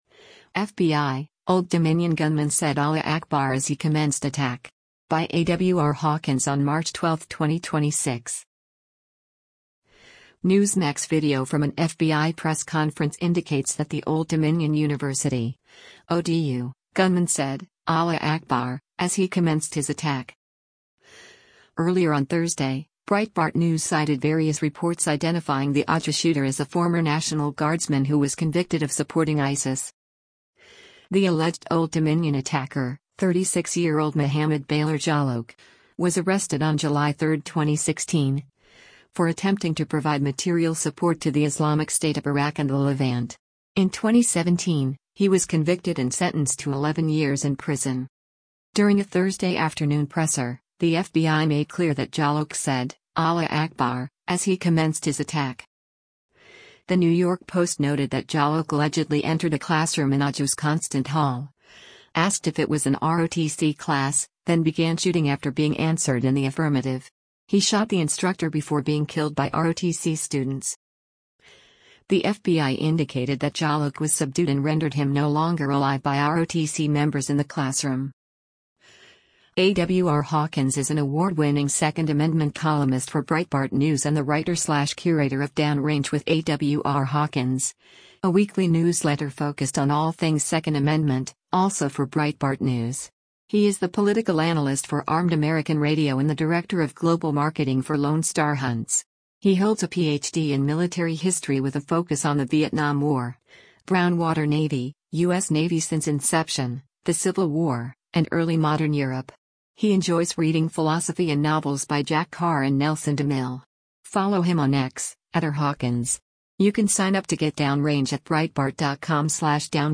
Newsmax video from an FBI press conference indicates that the Old Dominion University (ODU) gunman said, “Allah Akbar,” as he commenced his attack.